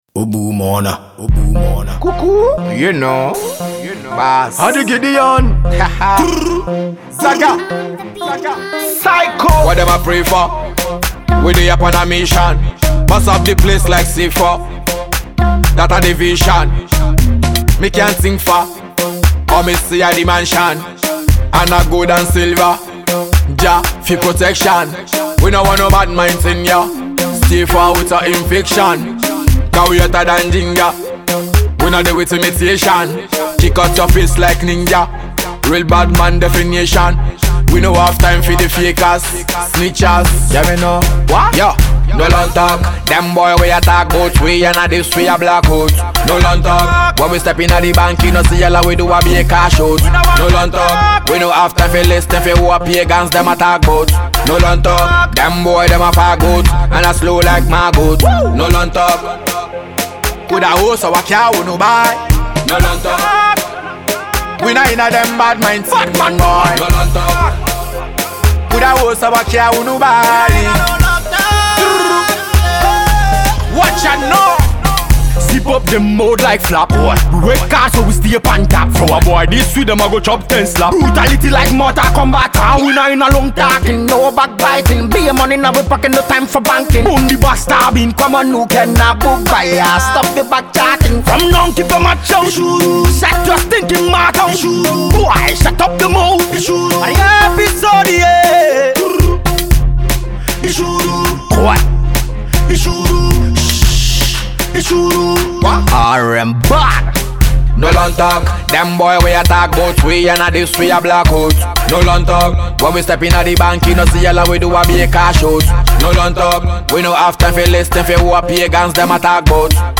Home Ghana Music Reggae/Dancehall Music Download